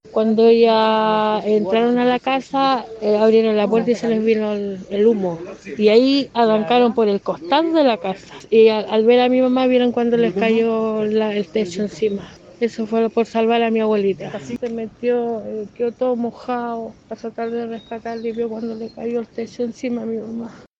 Una familiar de las víctimas relató que el techo de la vivienda cayó sobre las mujeres fallecidas cuando se intentaba rescatarlas.